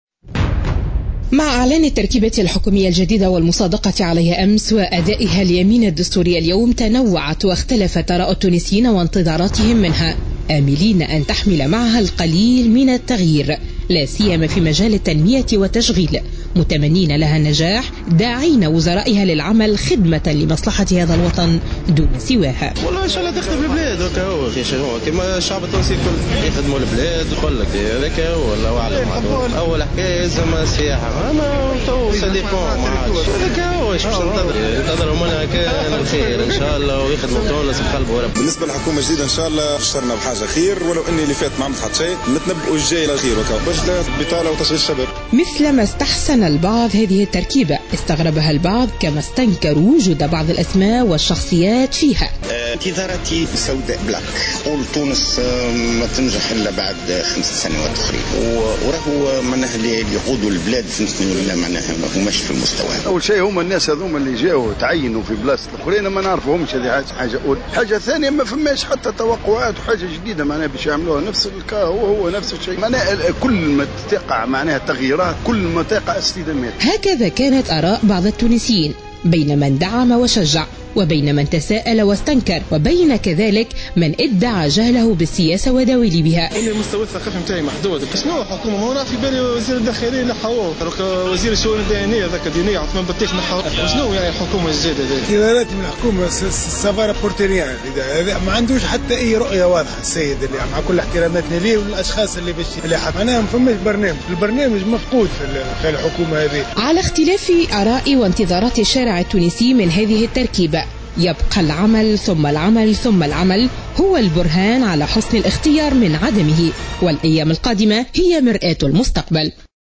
Les avis des habitants de Sousse concernant le remaniement ministériel du gouvernement d’Habib Essid étaient divergeants, entre acceptation, refus et indifférence.